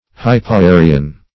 hypoarian - definition of hypoarian - synonyms, pronunciation, spelling from Free Dictionary Search Result for " hypoarian" : The Collaborative International Dictionary of English v.0.48: Hypoarian \Hy`po*a"ri*an\, a. (Anat.)